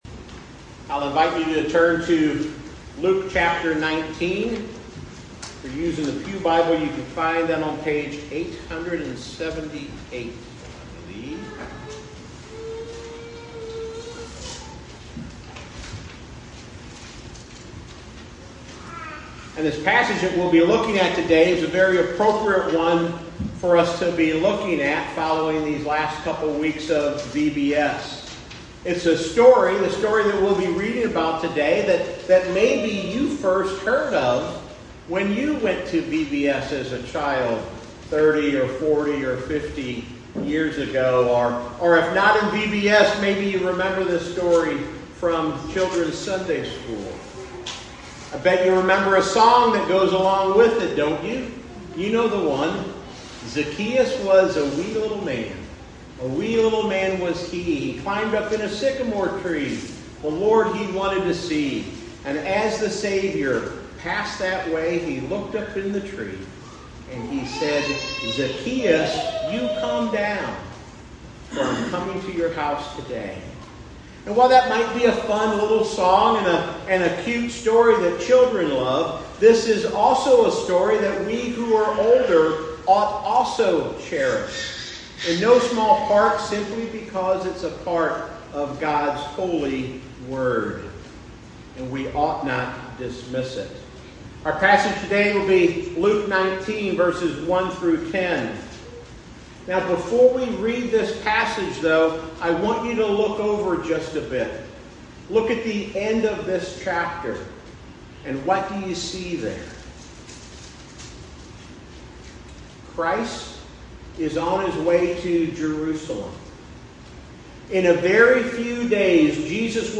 Sermon on Luke 19:1-10, New Port Presbyterian Church, July 27, 2025